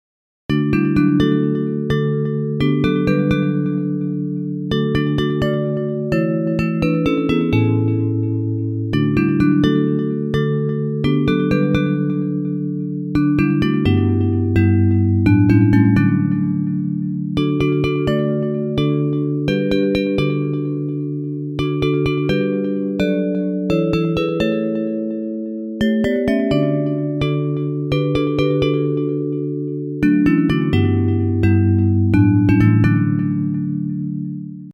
Traditional Hymns